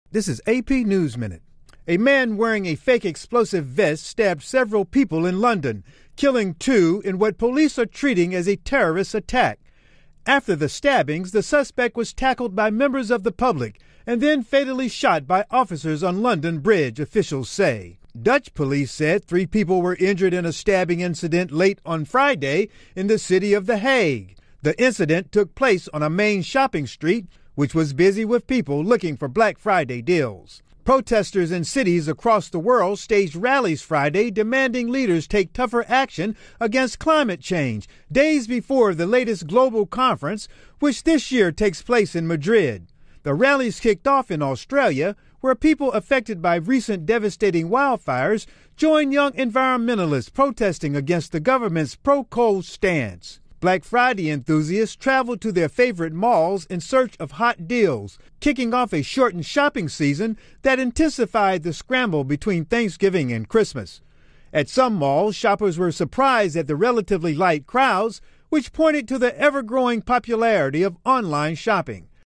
美语听力练习素材:伦敦桥发生持刀伤人事件